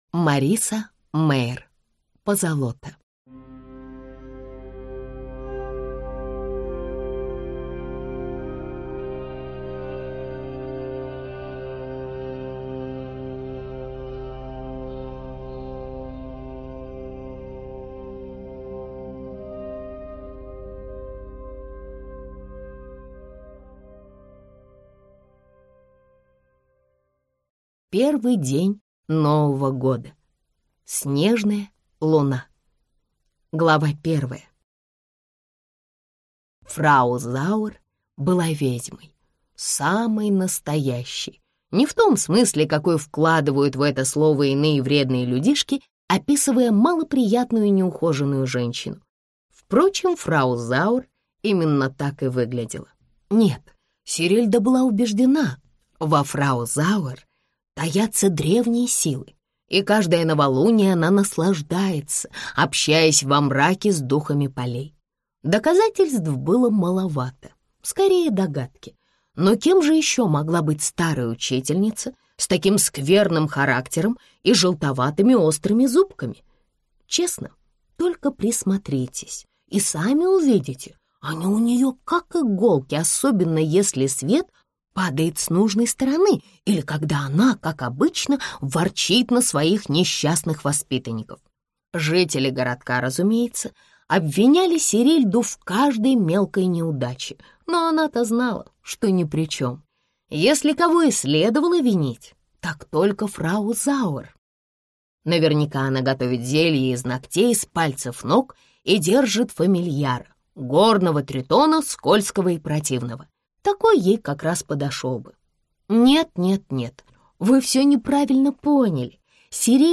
Аудиокнига Позолота | Библиотека аудиокниг
Прослушать и бесплатно скачать фрагмент аудиокниги